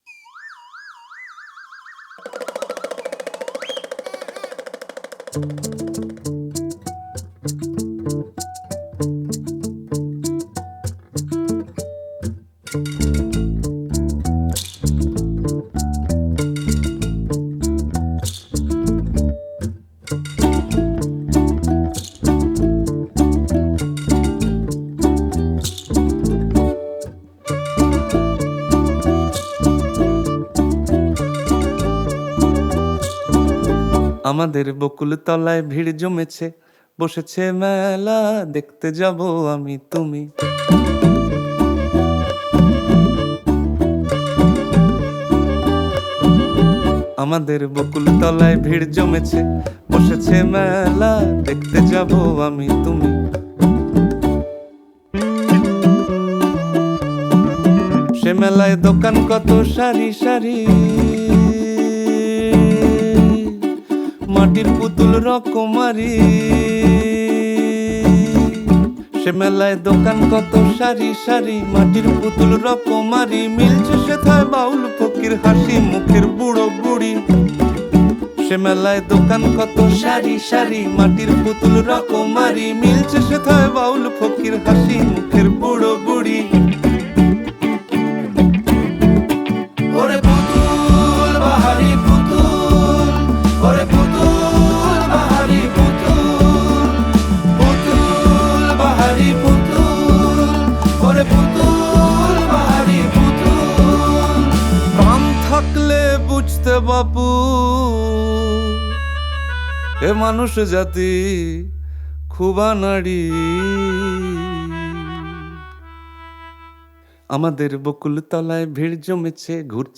Acoustic Guitar
Electric Guitar
Bass Guitar
Ukulele
Percussion
Flute & Melodica
Drums
Violin